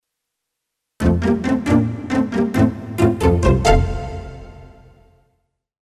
短めのサウンドエフェクト的な音楽素材集です。
ピチカートストリングver.